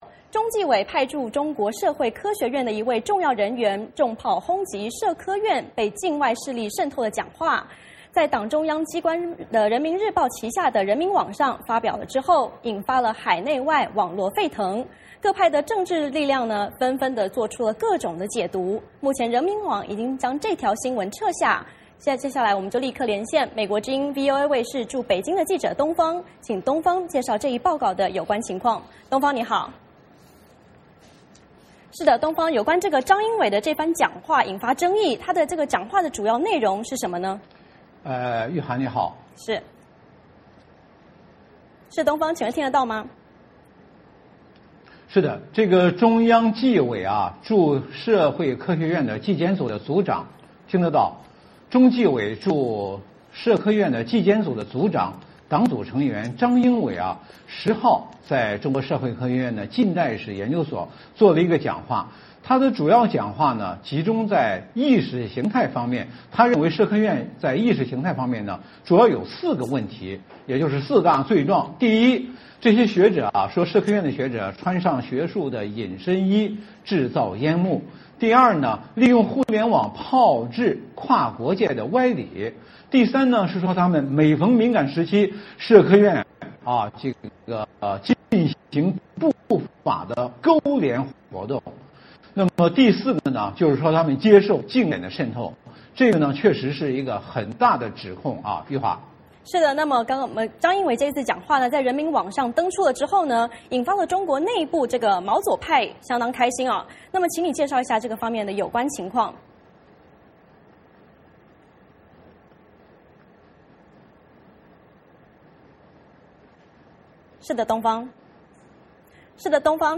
VOA连线：中纪委大员炮轰社科院引发舆情震动导致央媒撤稿